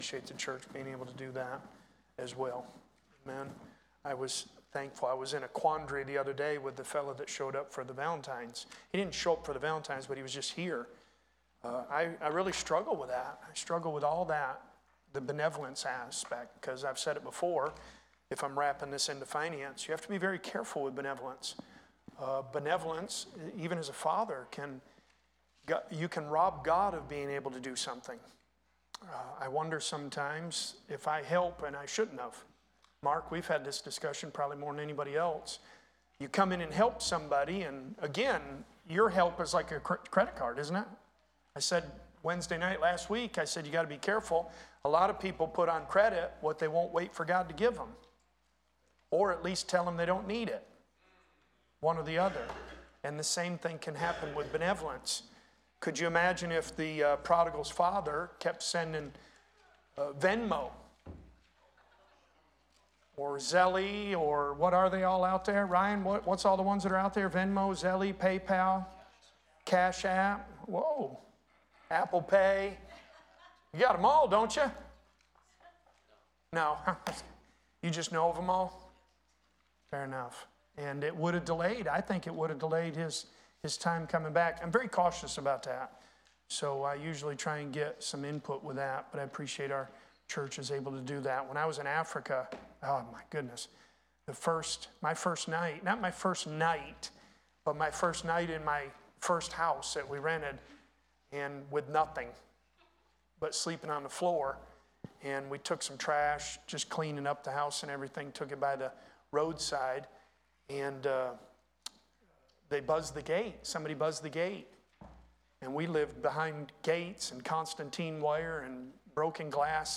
Sermons | Graham Road Baptist Church